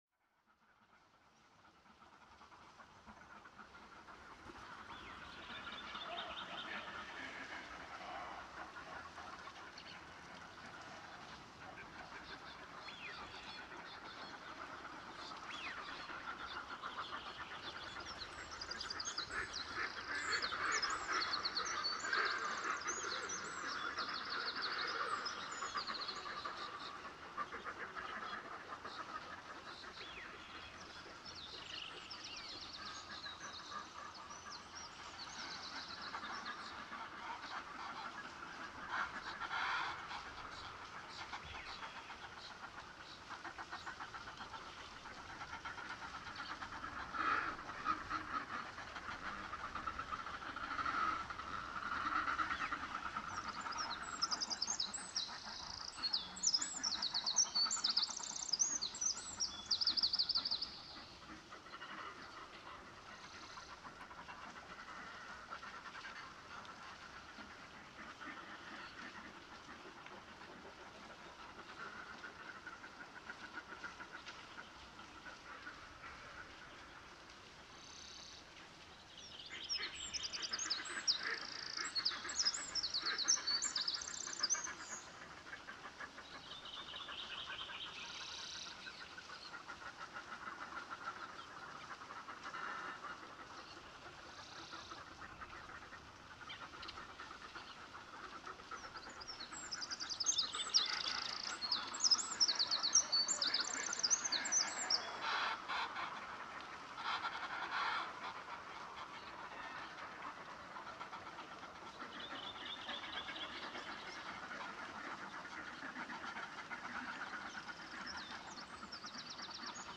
The pond is a home to a variety of waterfowl species like Wigeon and Red-necked Phalarope.
The recording was made around two am the sixth of June 2014.
Quality headphones are recommended while listening at medium-low level. Botnstjörn í Ásbygi Hér er á ferðinni næturupptaka sem gerð var kl 2 eftir miðnætti á útsýnispallinum við Botnsjörn í Ásbyrgi þann 6. júní 2014. Á upptökunni er helst að heyra í fýl ofan úr bjarginu, rauðhöfðaönd á tjörninni og nokkrum öðrum fuglategundum s.s. músarindli og skógarþresti innan úr skóginum. Þá steypist lítil lækjarspræna ofan af klettinum ofan í grjóturð framan við hljóðnemana.